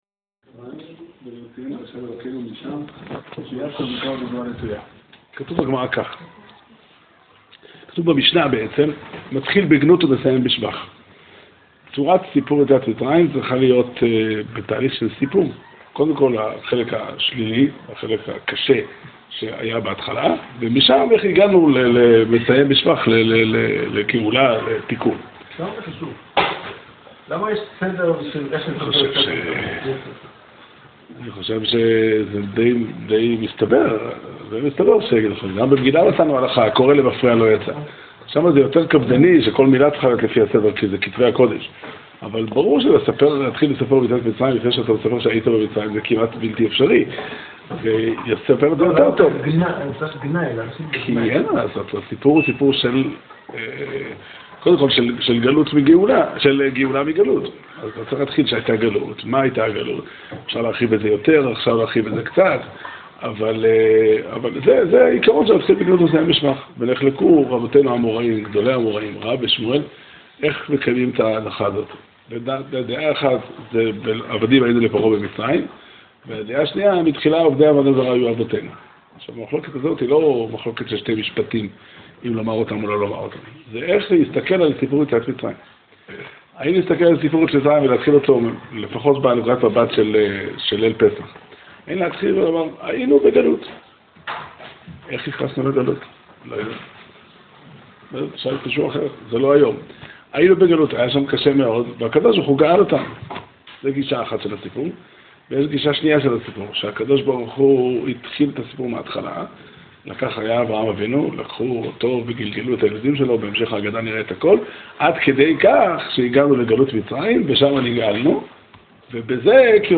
שיעור שנמסר בבית המדרש 'פתחי עולם' בתאריך כ"ה אדר תשע"ח